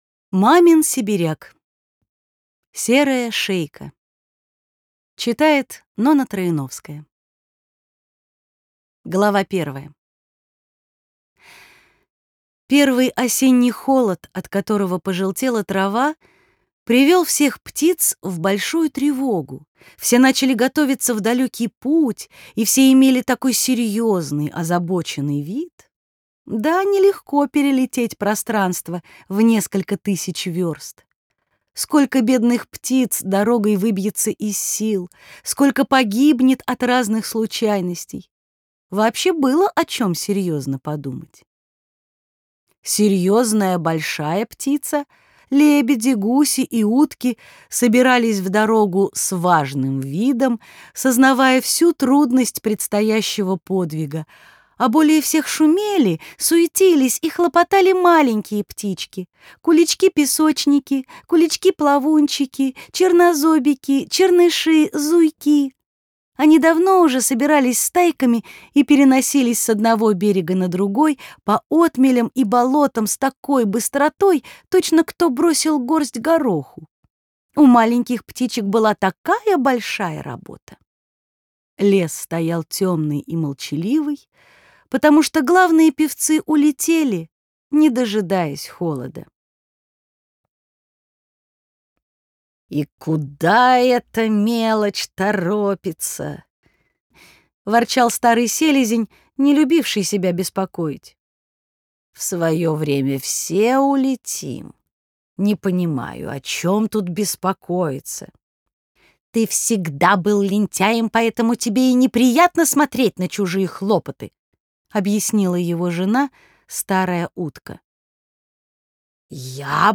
Бесплатная аудиокнига «Серая шейка» от Рексквер.
Классическую литературу в озвучке «Рексквер» легко слушать и понимать благодаря профессиональной актерской игре и качественному звуку.